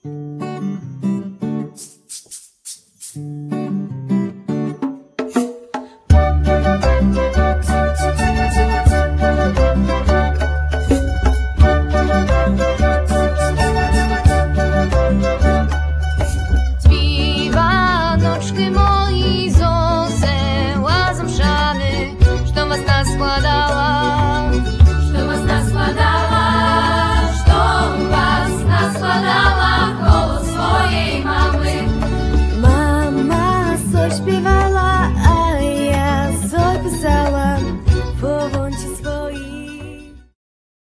sopiłka, flet poprzeczny
mandolina
gitara
skrzypce
bębny, djembe
akordeon
kontrabas